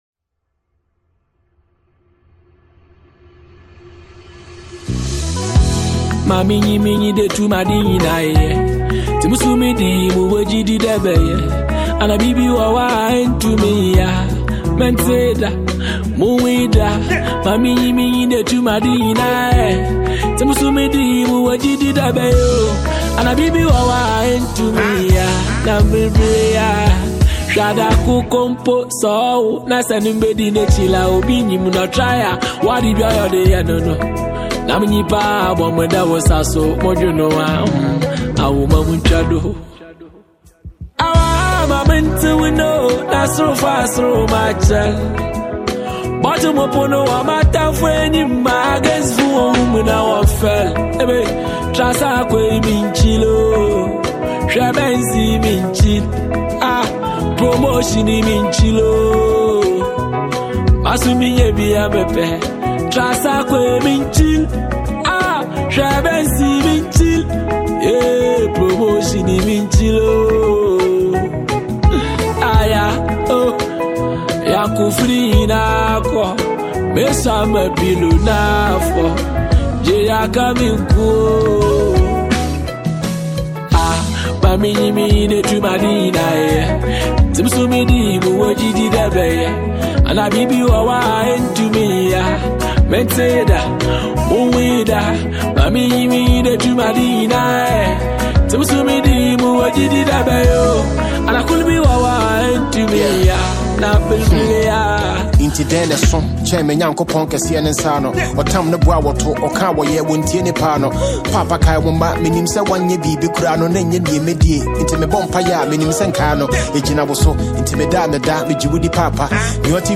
melodic delivery